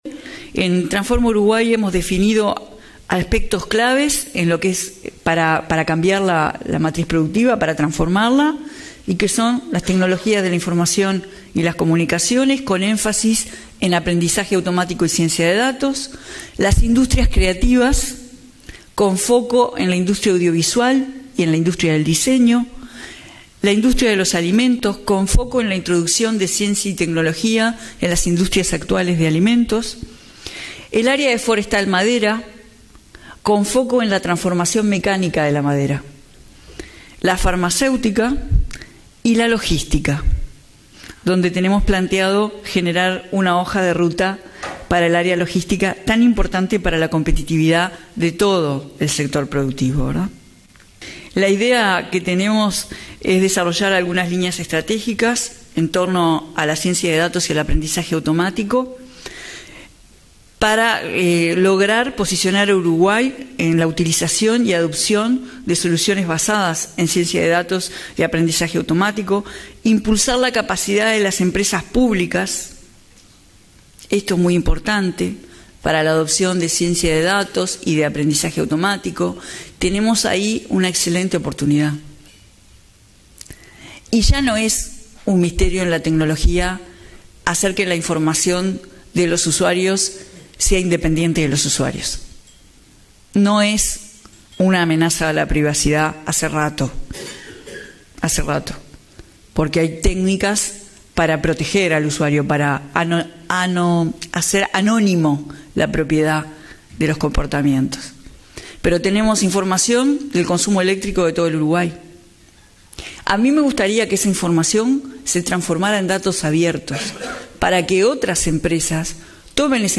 La ministra Carolina Cosse subrayó que se debe apostar al “aprendizaje automático” y a la ciencia de datos, una disciplina que se debe atender desde la educación hasta la producción. Al disertar en un desayuno de trabajo, adelantó que se lanzarán medidas de estímulo a las producciones internacionales y nacionales (task break), a fin de que se desarrollen en Uruguay producciones de relevancia.